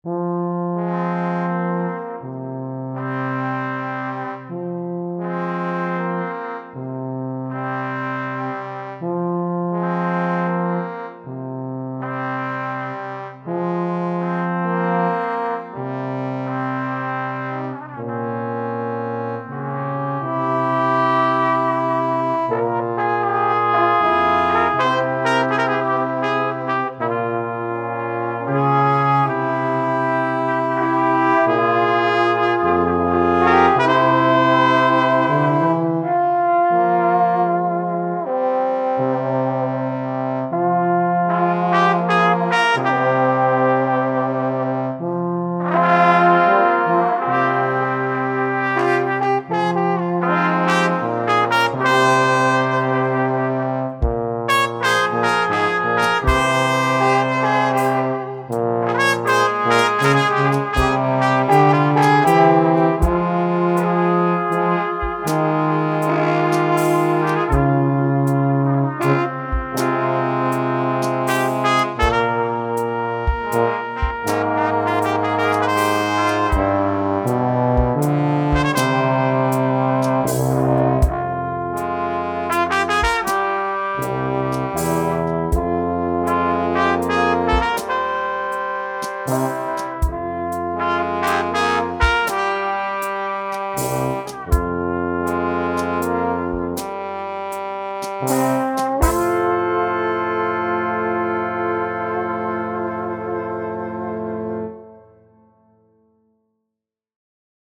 Genre: Documentary Underscore.